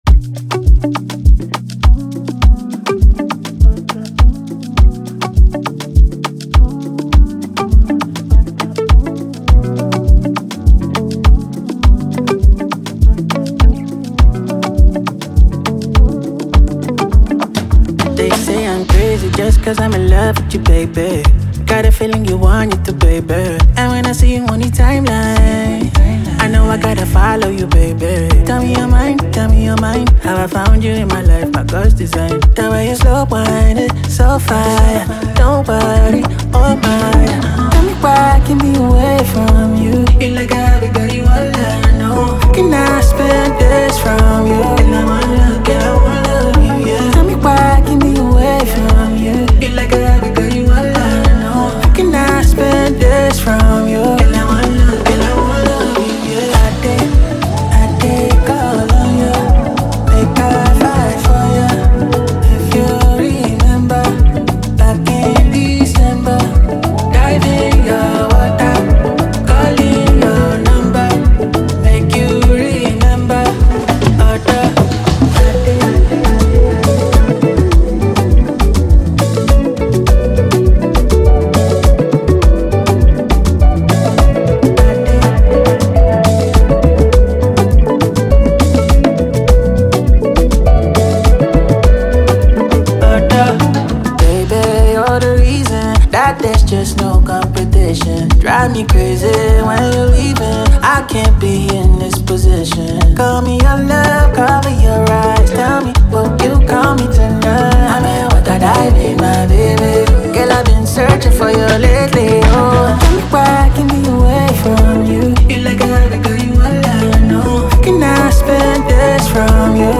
Talented Nigerian-Canadian singer and songwriter